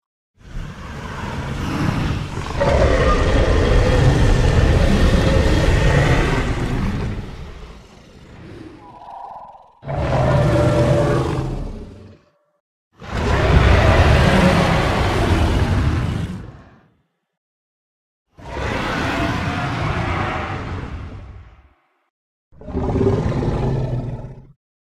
Klingelton Dinosaur Sound